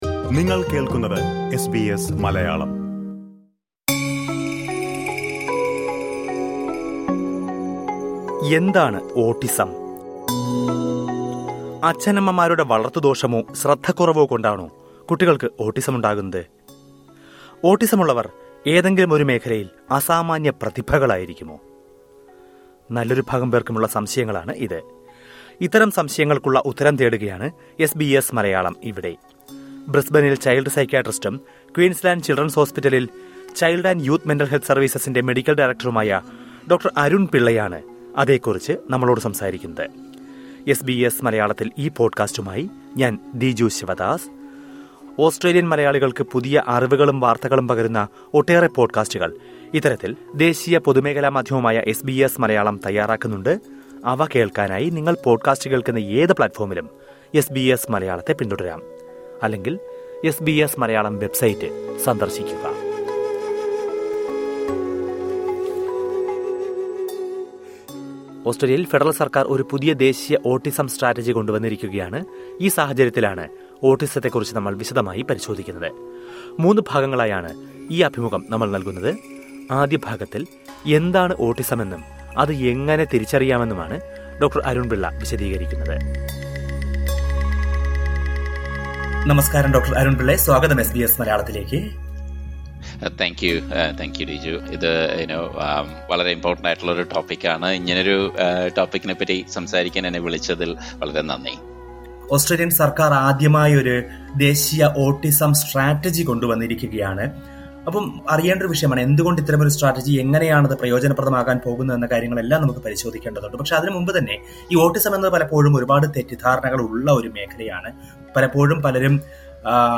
അഭിമുഖത്തിന്റെ ആദ്യഭാഗം കേള്‍ക്കാം, മുകളിലെ പ്ലേയറില്‍ നിന്ന്...